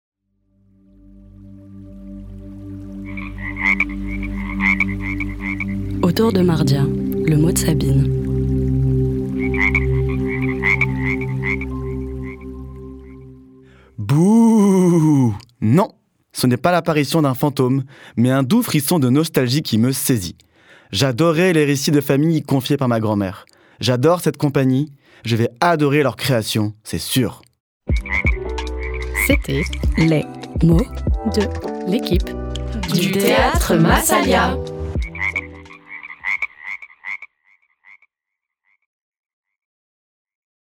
· Extrait sonore - Une musique du spectacle